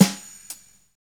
56BRUSHSD2-L.wav